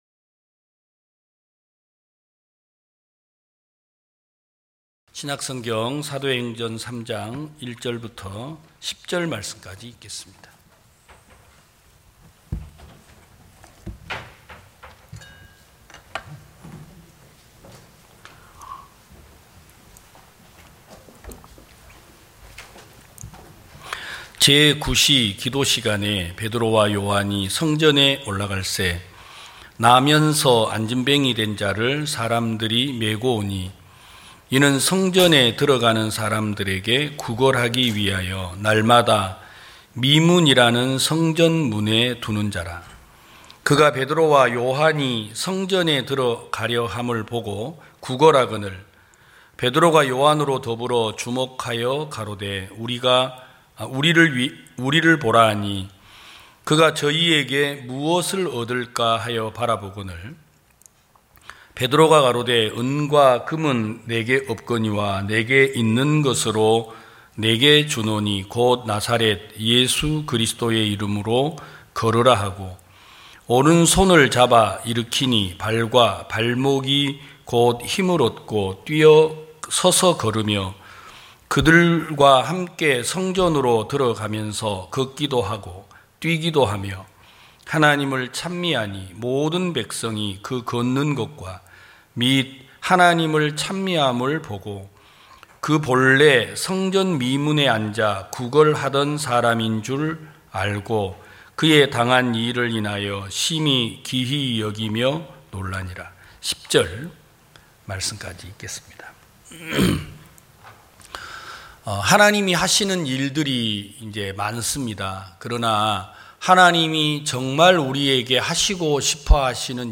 2022년 07월 03일 기쁜소식부산대연교회 주일오전예배
성도들이 모두 교회에 모여 말씀을 듣는 주일 예배의 설교는, 한 주간 우리 마음을 채웠던 생각을 내려두고 하나님의 말씀으로 가득 채우는 시간입니다.